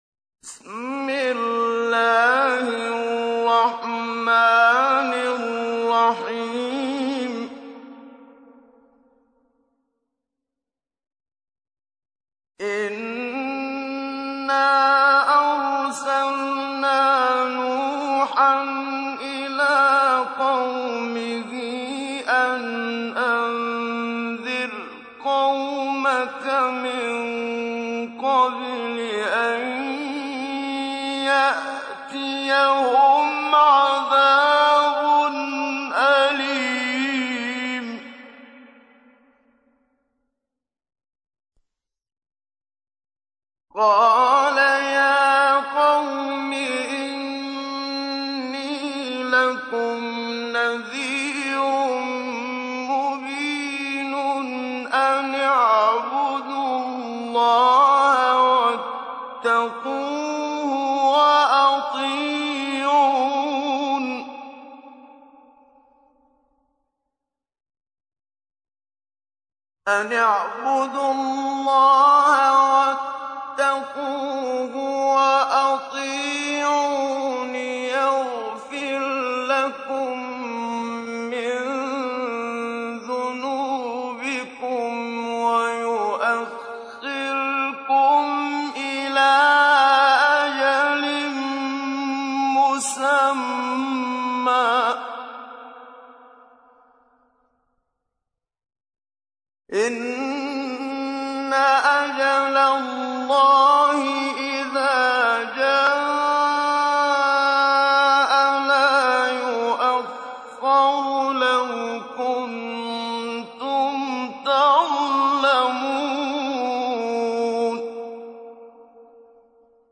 تحميل : 71. سورة نوح / القارئ محمد صديق المنشاوي / القرآن الكريم / موقع يا حسين